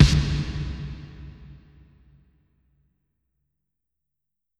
Snare (Paranoid).wav